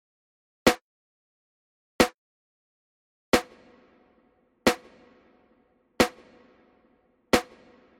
Zunächst kommen zwei Snare Drum Schläge trocken, dann mit Hall bei -18 dB.
Vienna ConcertHall:
ircam_verb_testbericht_04_vienna_concert_hall.mp3